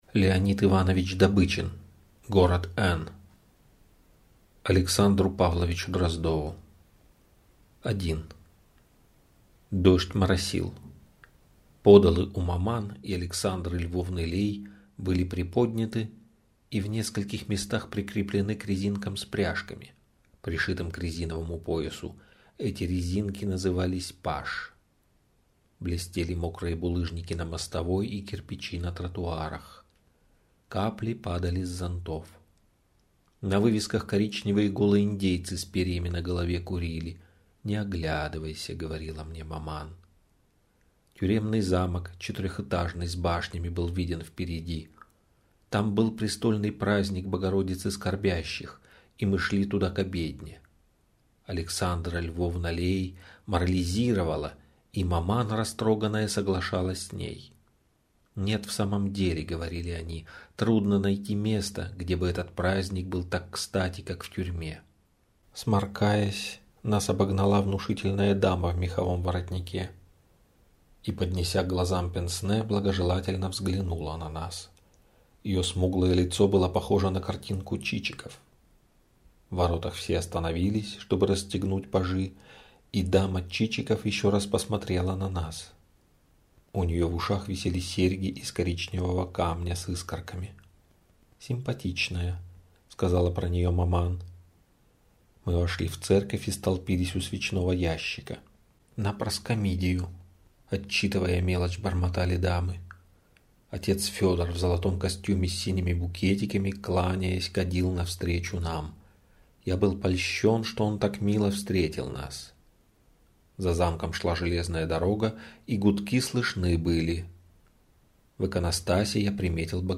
Аудиокнига Город Эн | Библиотека аудиокниг